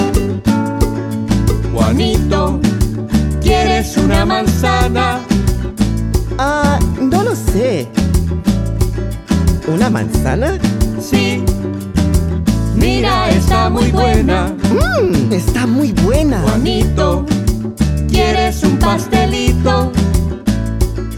This Spanish song for kids